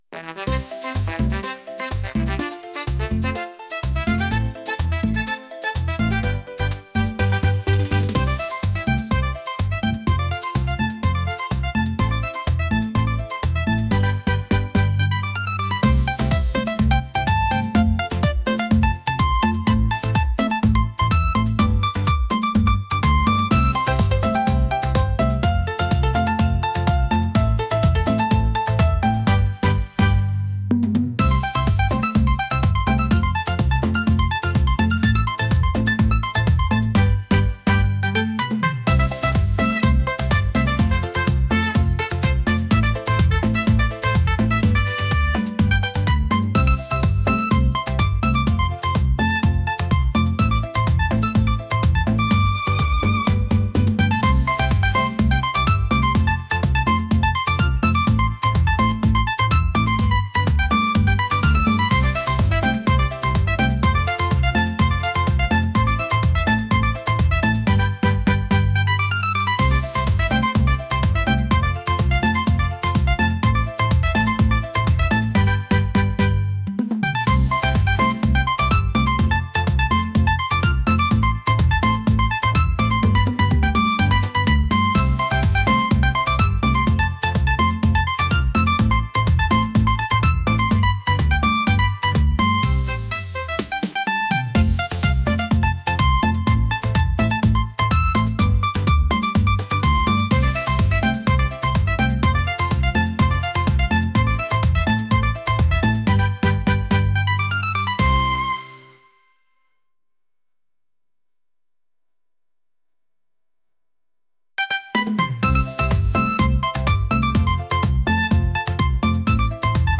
latin | upbeat